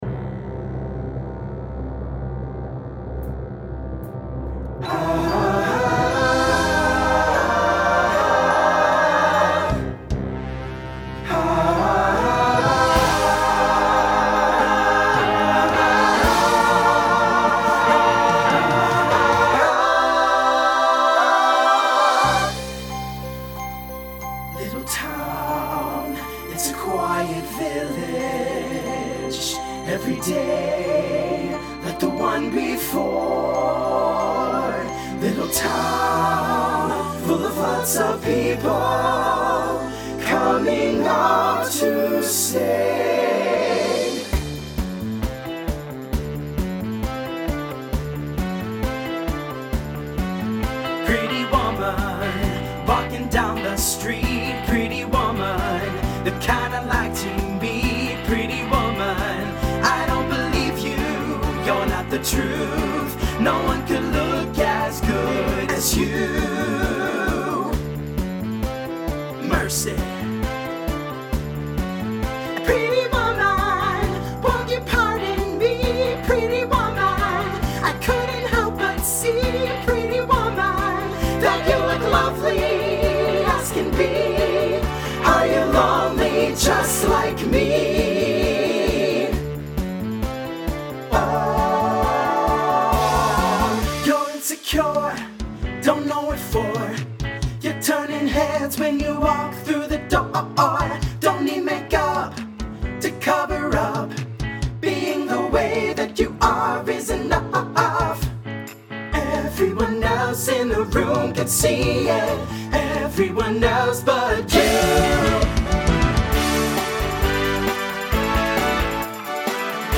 SATB Instrumental combo
Pop/Dance , Rock